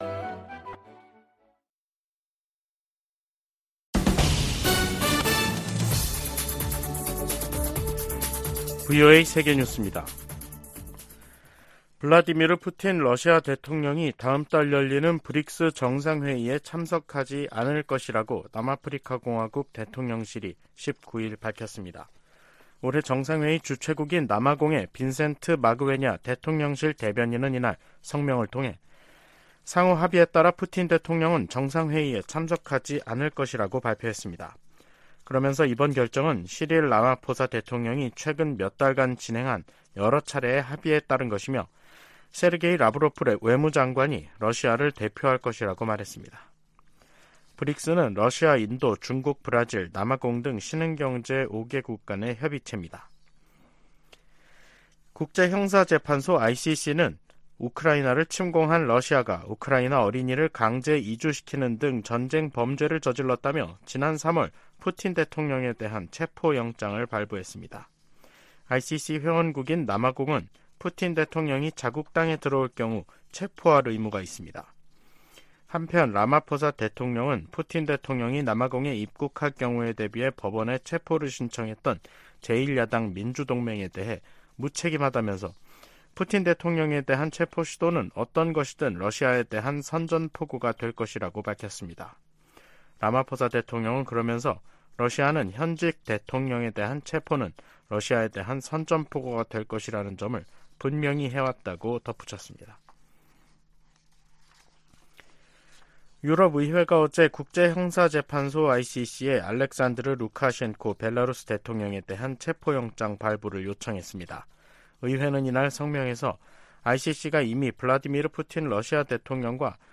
VOA 한국어 간판 뉴스 프로그램 '뉴스 투데이', 2023년 7월 19일 3부 방송입니다. 로이드 오스틴 미 국방장관이 판문점 공동경비구역을 견학하던 미군의 월북을 확인했습니다.